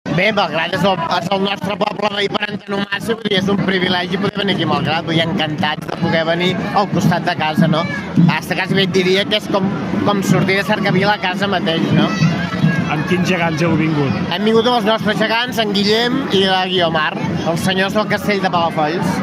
un dels seus membres, ha explicat què ha significat per a l’entitat el fet de participar a la trobada de gegants de Malgrat de Mar.